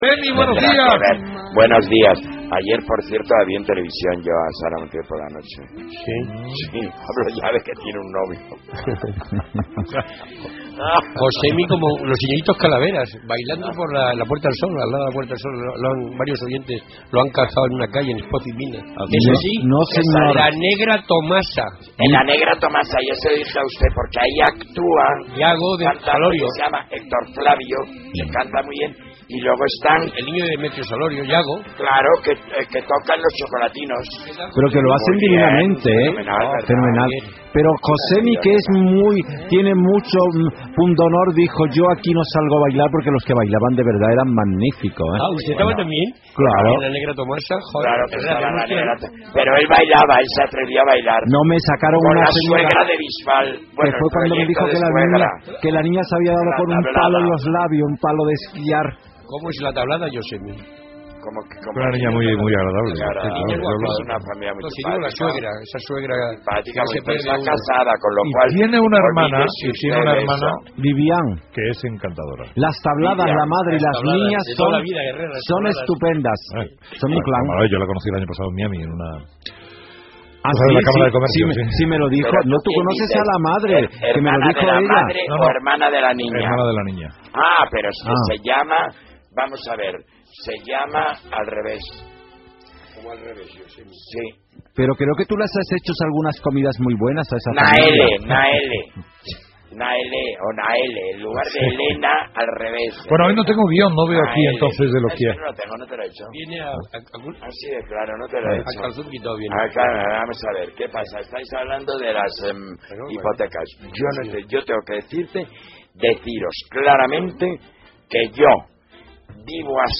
bajo
timbales
congas
voz